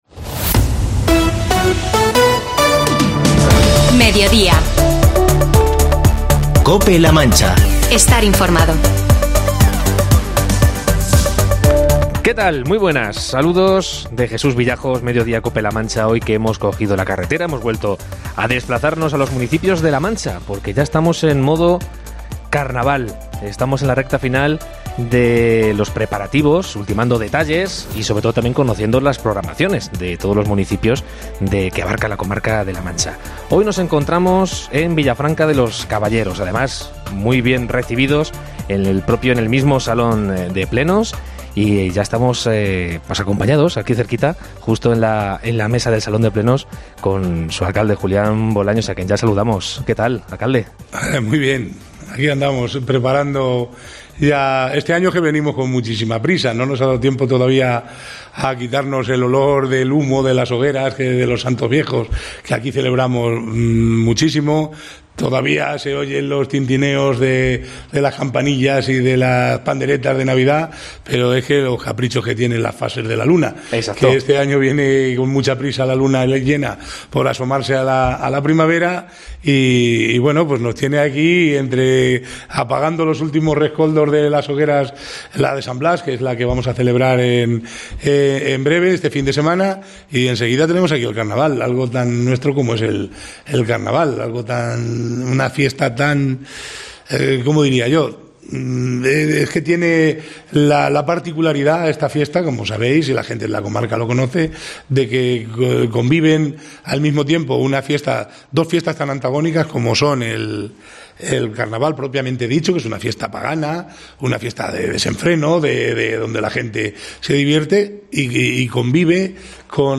Entrevista al alcalde de Villafranca de los Caballeros, Julián Bolaños, y concejal de Festejos, Carlos Gómez, por su Carnaval 2024
AUDIO: Mediodía Cope La Mancha se desplaza a la mancha toledana, al salón de plenos del Ayuntamiento de Villafranca de los Caballeros para...